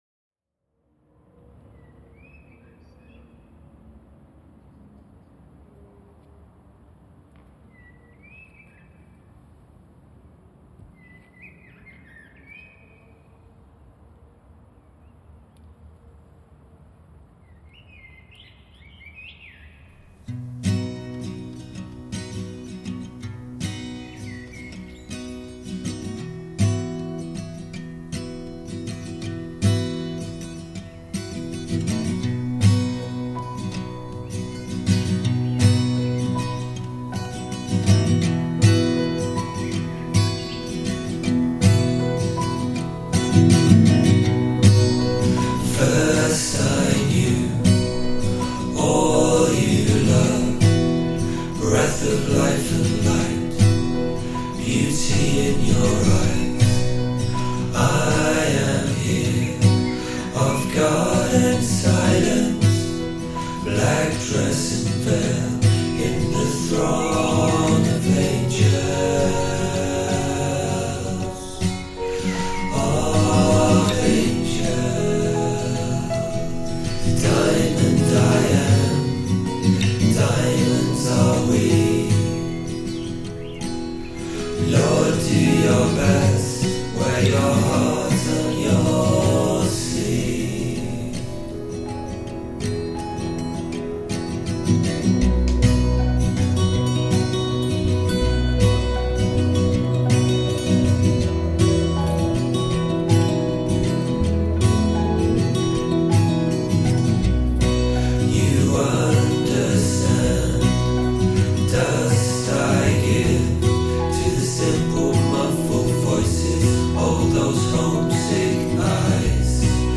When I first listened to the recording of the depopulated village in Jerusalem, what really stood out was the bird song sounding like a voice of hope.
Jerusalem depopulated village